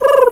Animal_Impersonations
pigeon_2_emote_05.wav